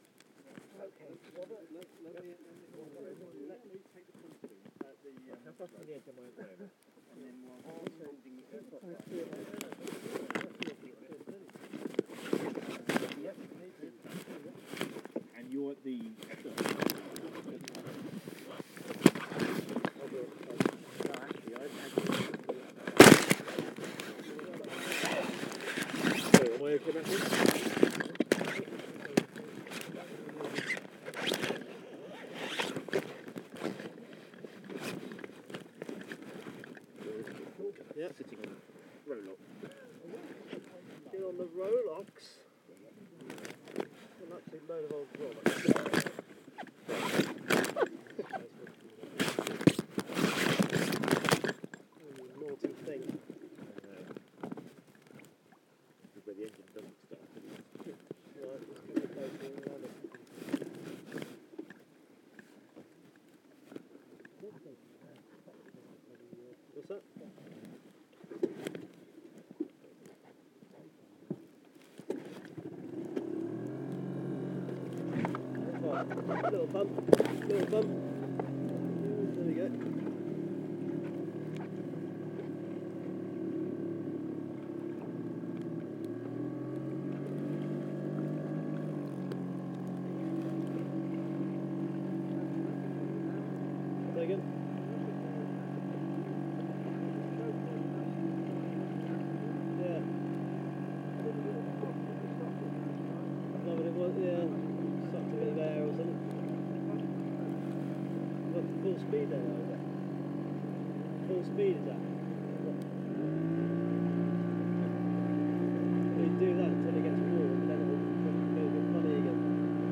Recorded from inside my dry bag.